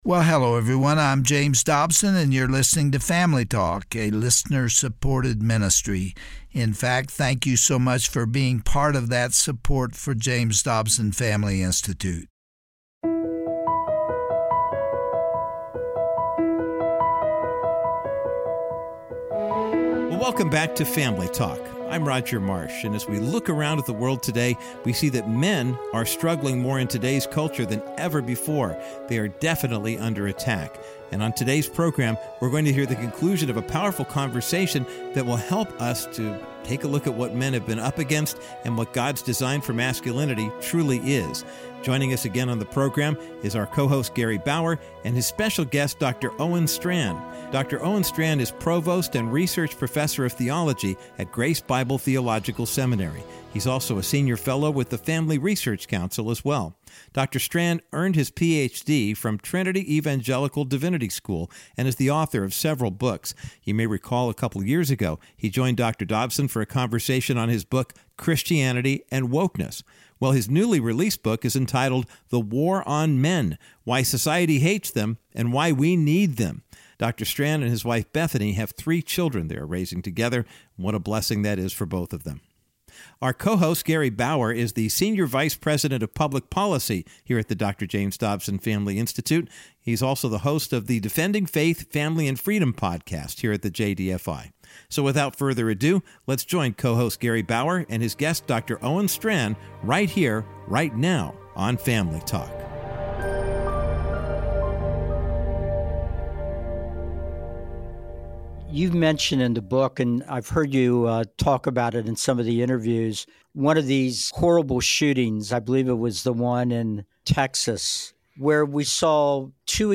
Host Gary Bauer